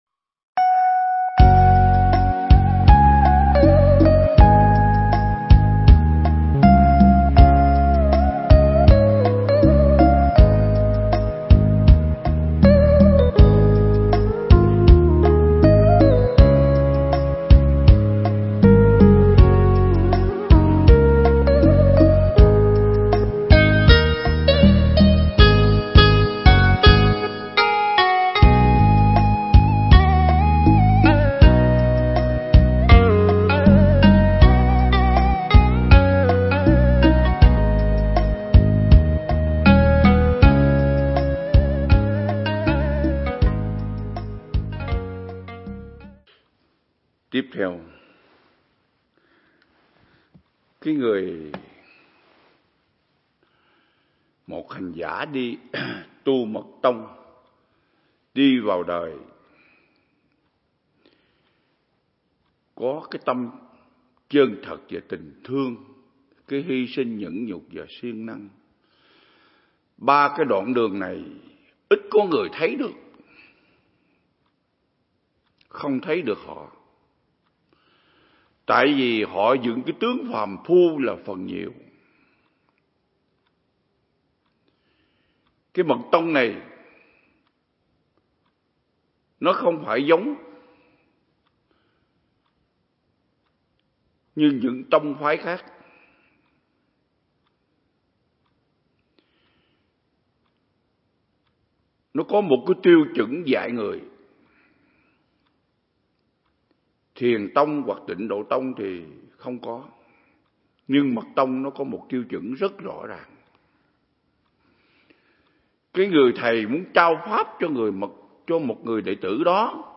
Pháp Thoại
Viện Nghiên Cứu Và Ứng Dụng Buddha Yoga Việt Nam (TP Đà Lạt)